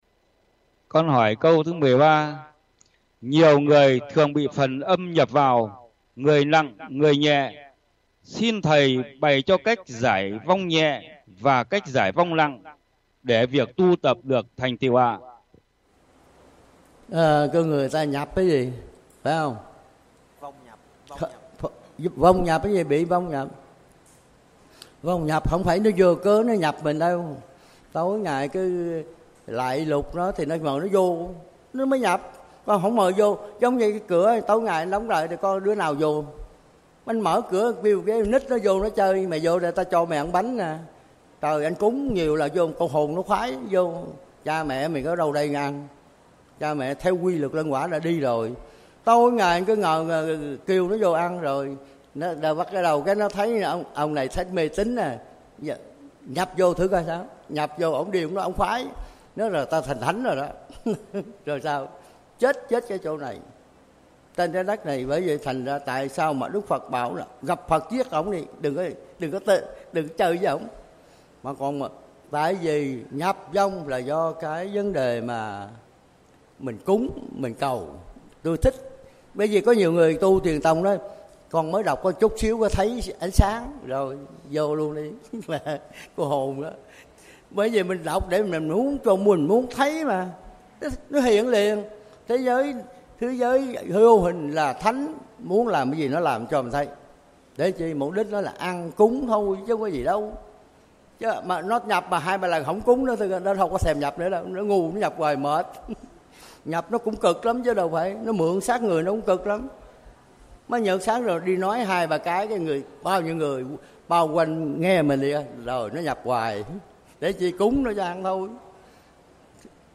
Trò hỏi
Thầy trả lời: